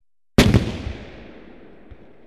Add sound effect to cannon 1-3
cannon.mp3